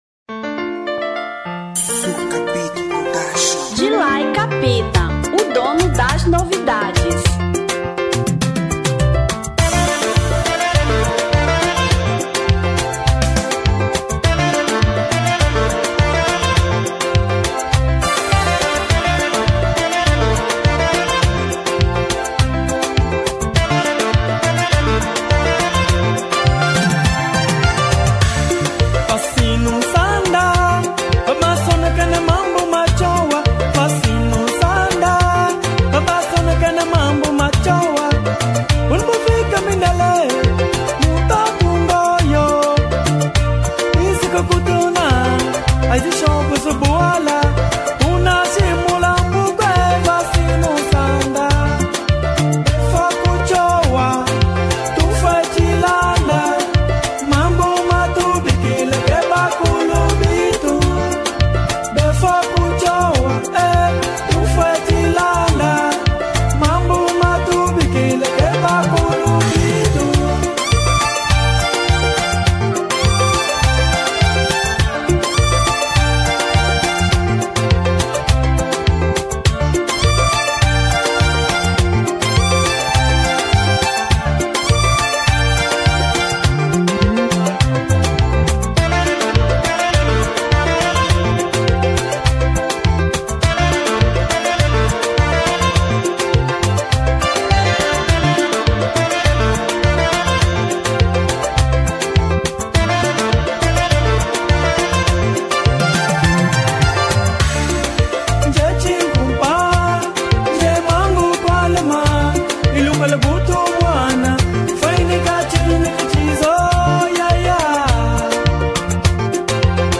Kizomba 2003